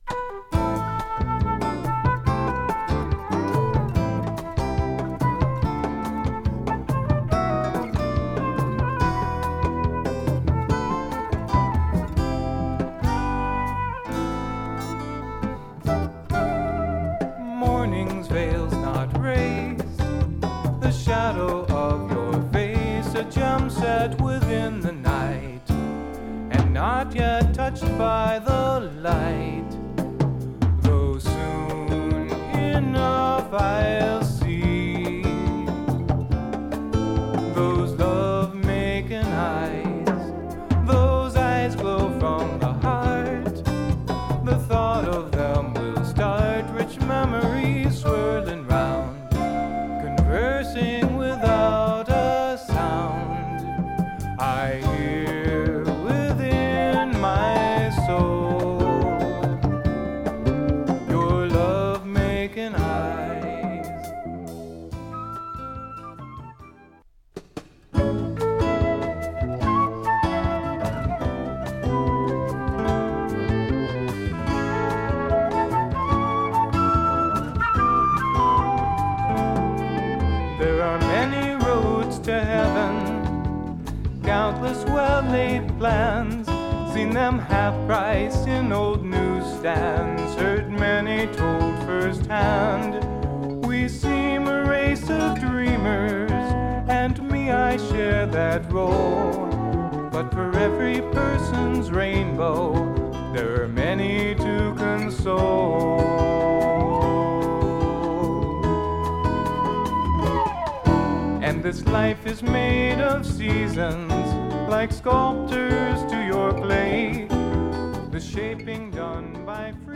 ボサノバを基調とした極上のメロウSSW作品。
ピアノの効いたワルツでジャジーな